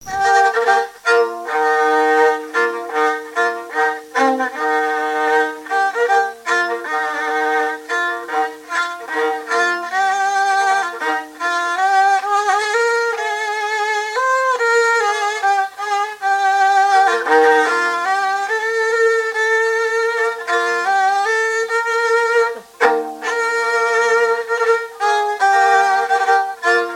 Localisation Montreuil (Plus d'informations sur Wikipedia)
Catégorie Pièce musicale inédite